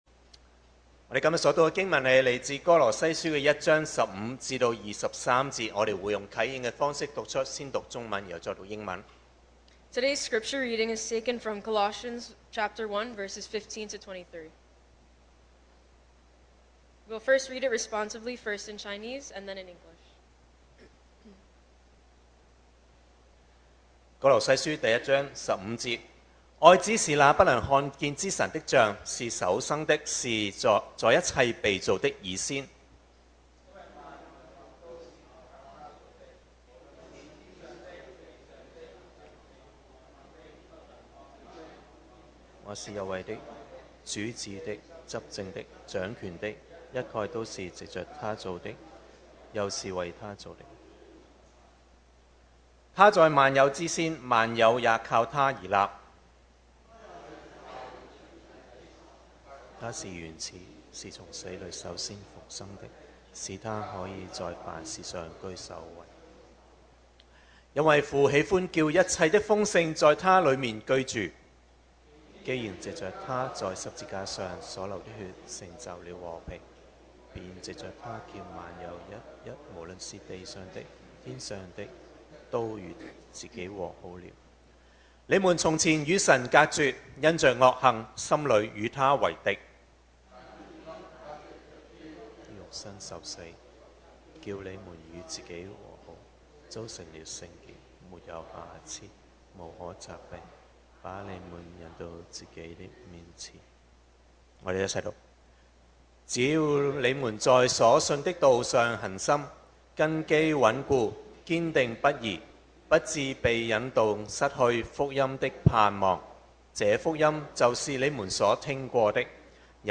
2025 sermon audios 2025年講道重溫 Passage: Colossians 1:15-23 Service Type: Sunday Morning My Lord and my God!